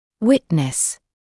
[‘wɪtnəs][‘уитнэс]быть свидетелем (чего-л.); видеть, наблюдать; свидетель, очевидец